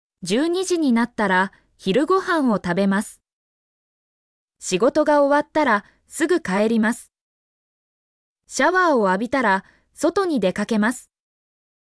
mp3-output-ttsfreedotcom-80_p6vmvxTS.mp3